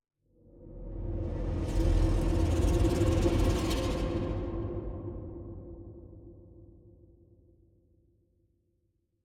Minecraft Version Minecraft Version 25w18a Latest Release | Latest Snapshot 25w18a / assets / minecraft / sounds / ambient / nether / basalt_deltas / twist2.ogg Compare With Compare With Latest Release | Latest Snapshot